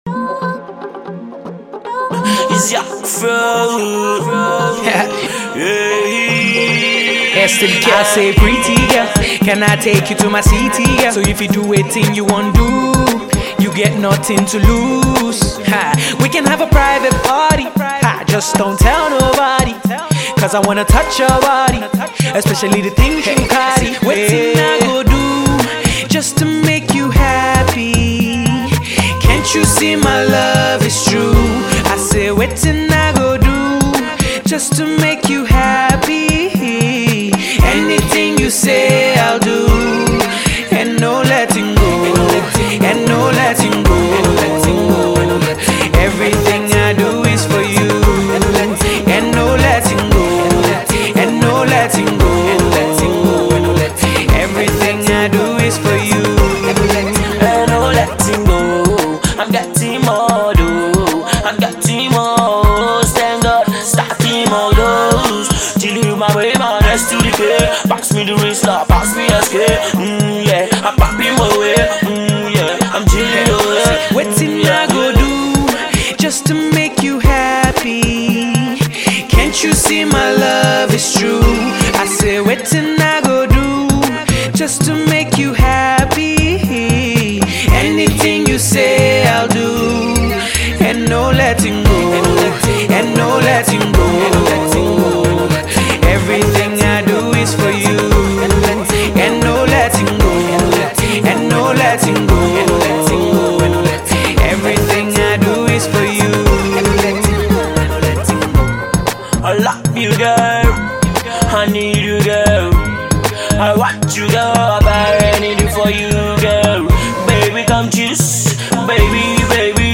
laidback